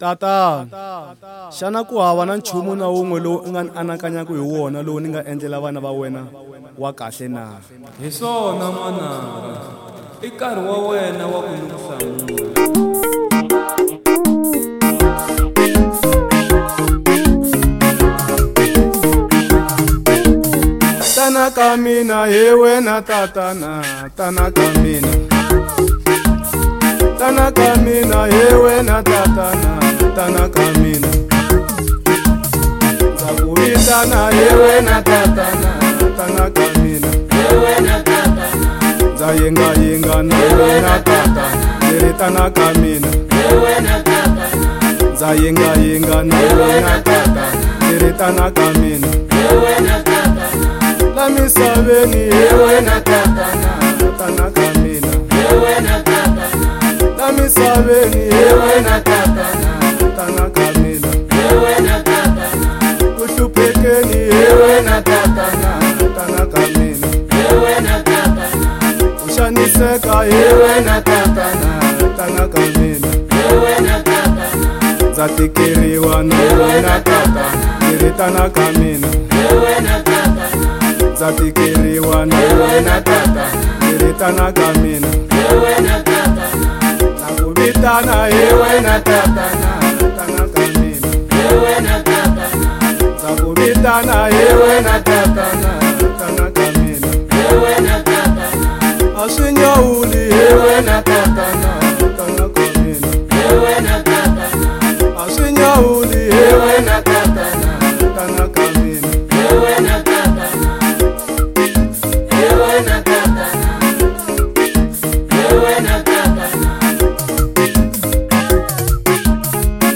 05:22 Genre : Gospel Size